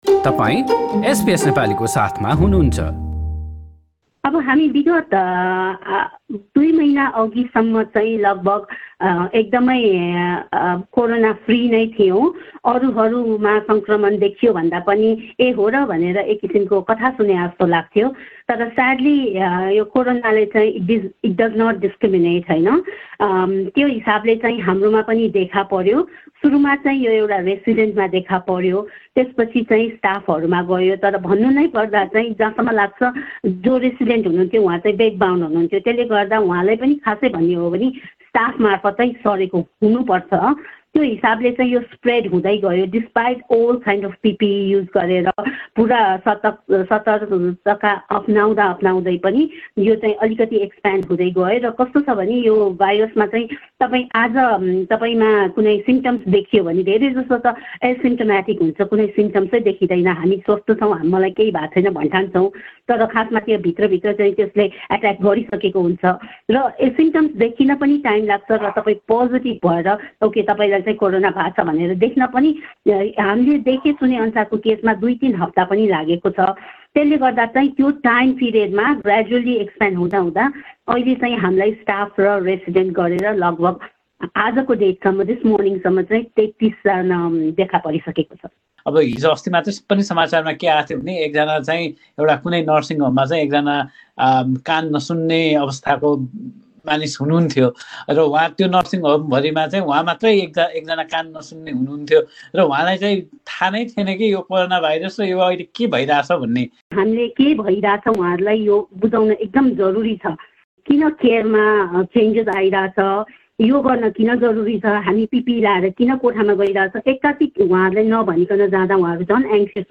कुराकानीको अंश